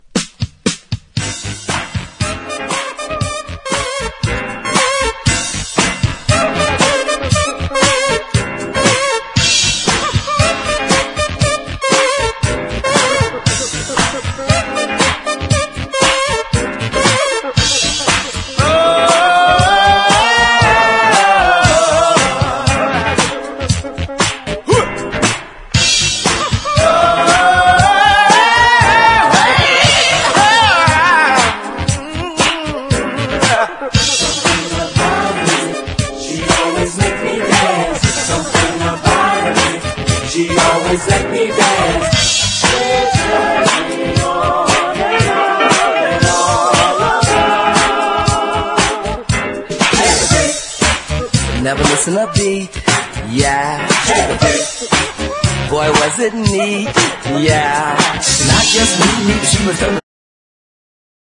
SOUL / SOUL / FREE SOUL / DISCO / DRUM BREAK
アレンジもソフト・ロック～フリー・ソウル、もちろんディスコ・ファン～DJにまでお薦めな一枚。